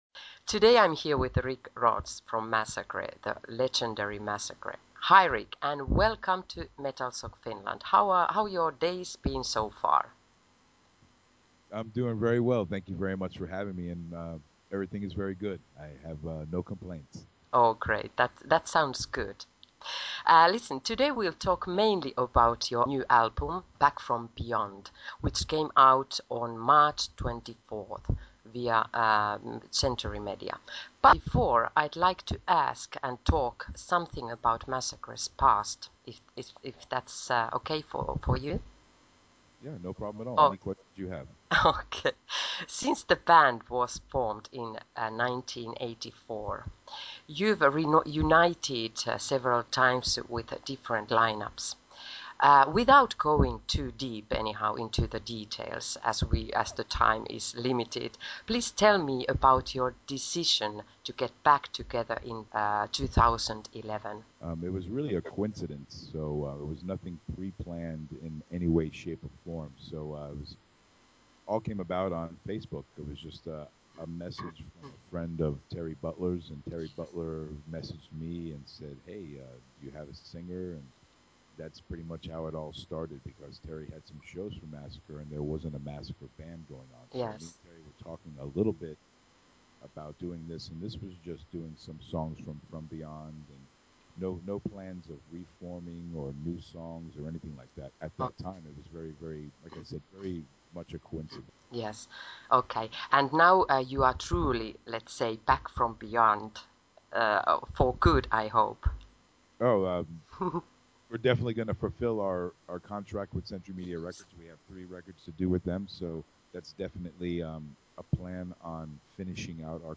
Audio Interview With MASSACRE: Rick Rozz Talks About “Back From Beyond” And Upcoming Tours, Reveals Backstage Past Scenes
interviewmassacre-rickrozz-4april2014-edited.mp3